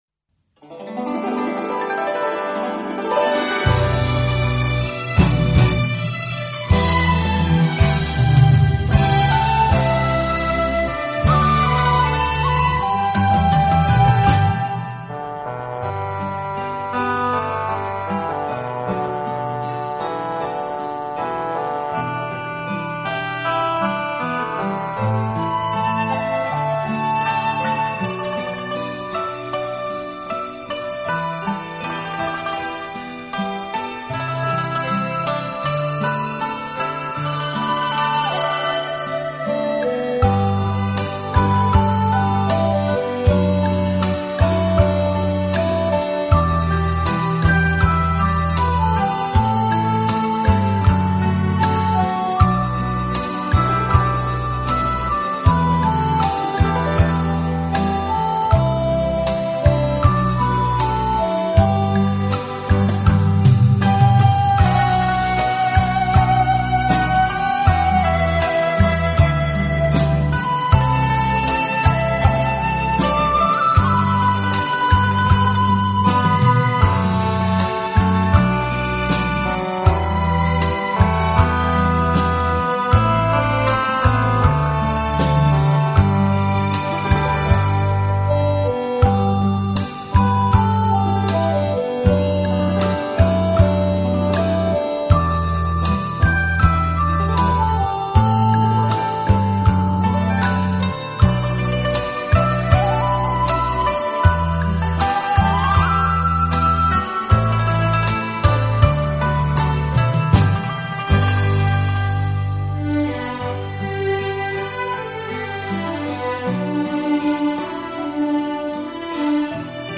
类别: 轻音乐
优雅略带凄美的曲子，漫山遍野的红枫叶，装扮出衣服浓浓